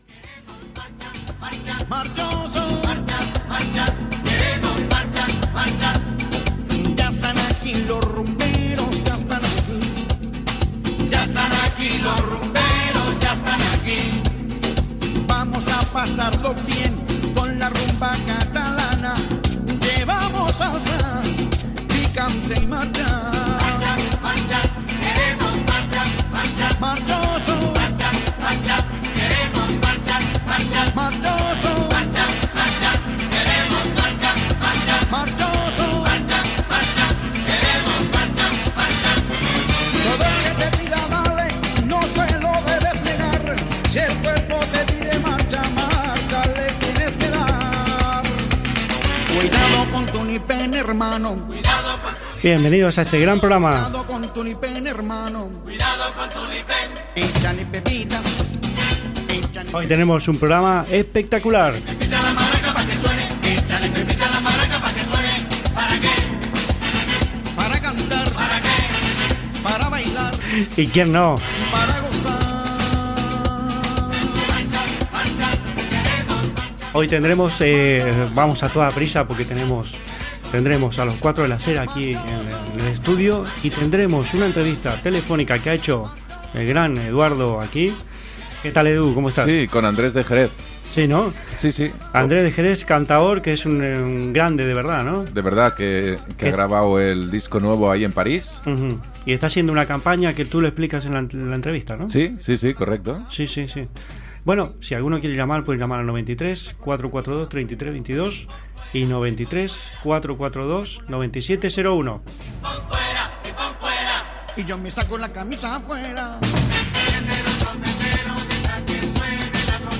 Sintonia, presentació, continguts, telèfon i entrevista telefónica
Gènere radiofònic Musical